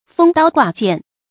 封刀挂剑 fēng dāo guà jiàn
封刀挂剑发音